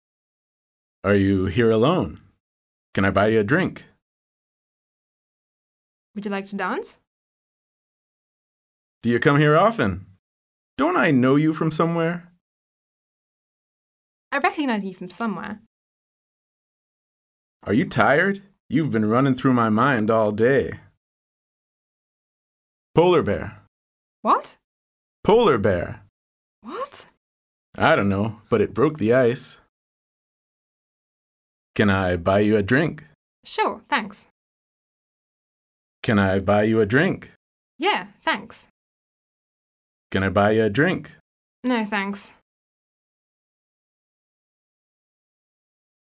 Nem hiszed el, de profi anyanyelviek pironkodás nélkül felmondták a fenti szöveget (Hallgasd csak meg!és a könyv szinte teljes szövegét.